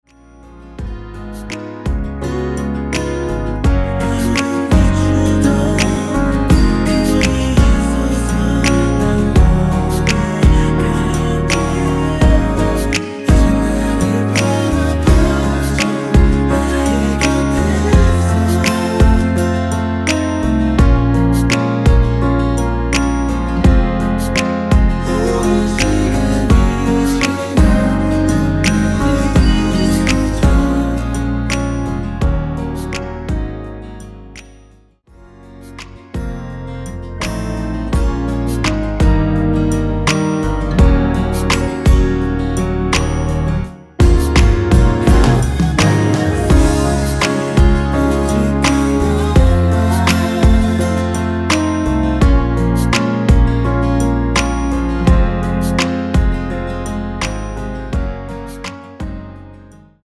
원키에서(-1)내린 코러스 포함된 MR입니다.
Db
◈ 곡명 옆 (-1)은 반음 내림, (+1)은 반음 올림 입니다.